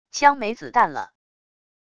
枪没子弹了wav音频